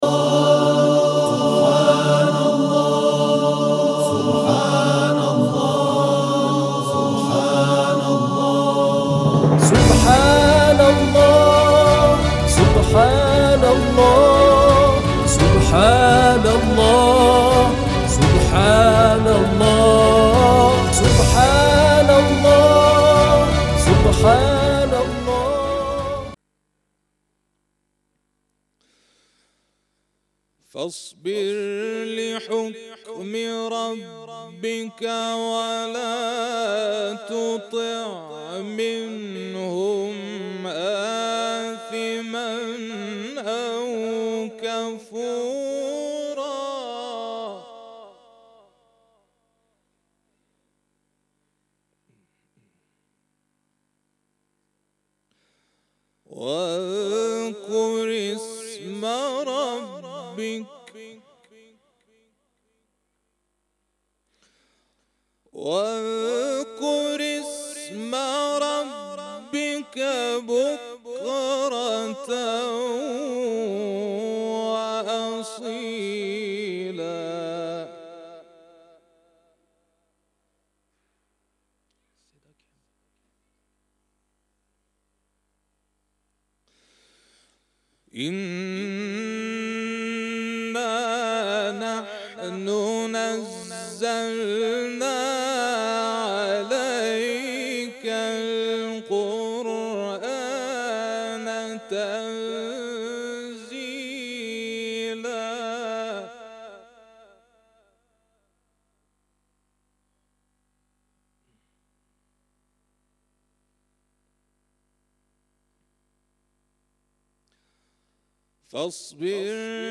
تلاوتی جدید
به گزارش خبرنگار فرهنگی باشگاه خبرنگاران پویا، اختتامیه بخش اول مسابقات سراسری قرآن کریم شب گذشته در ارومیه برگزار شد.
در ادامه شنونده این تلاوت باشید.